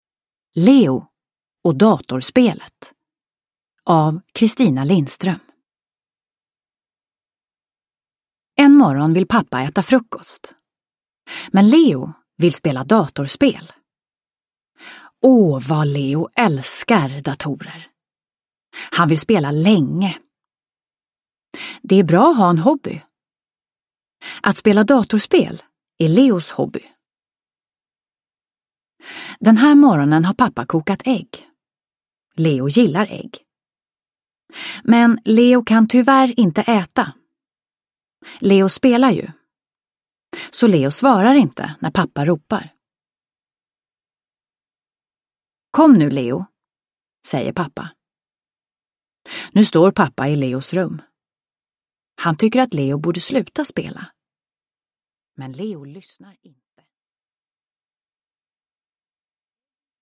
Leo och datorspelet – Ljudbok – Laddas ner